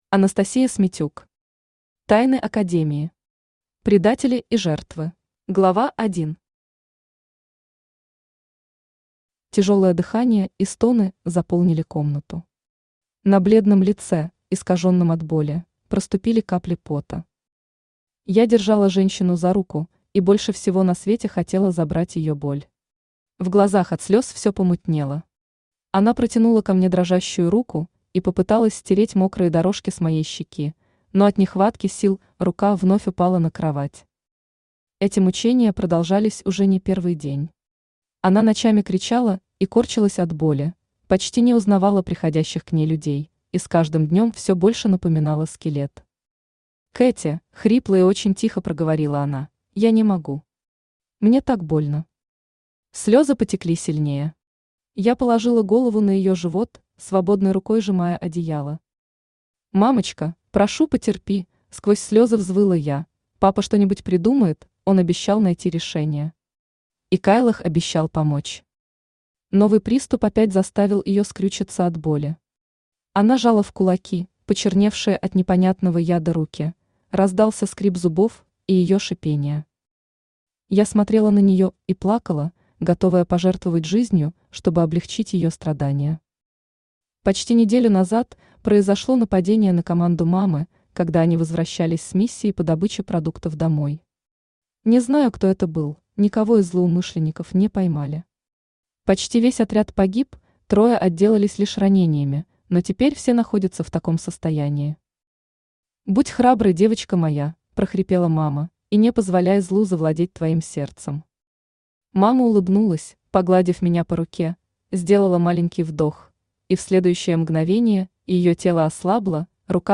Предатели и жертвы Автор Анастасия Смитюк Читает аудиокнигу Авточтец ЛитРес.